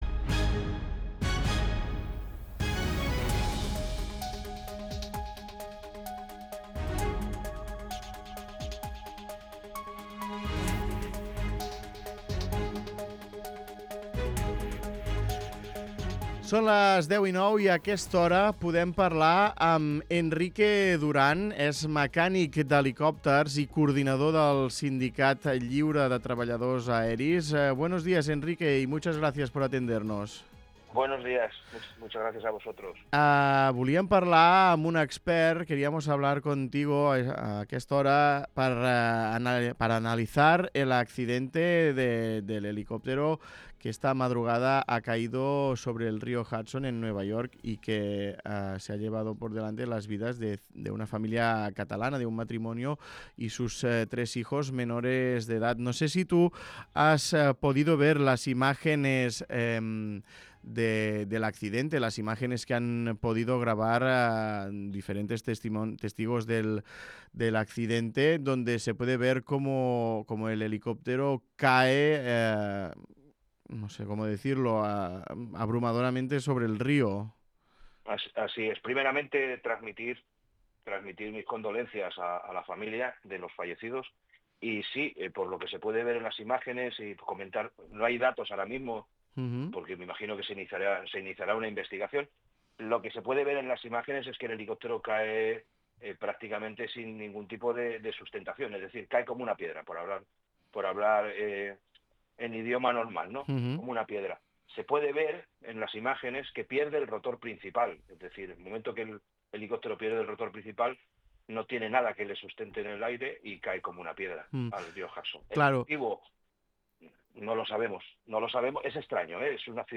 Escola l'entrevista